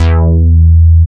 75.02 BASS.wav